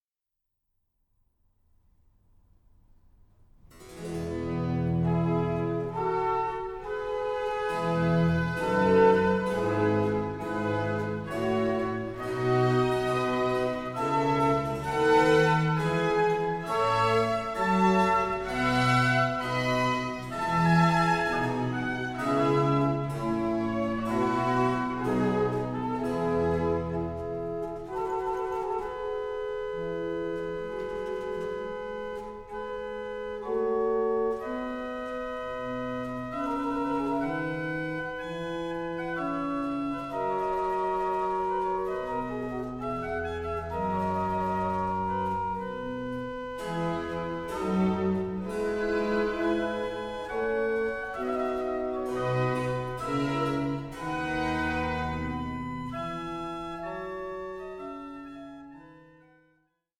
Organ Concerto No.5 in F, Op.4 No.5